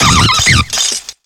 Cri de Vortente dans Pokémon X et Y.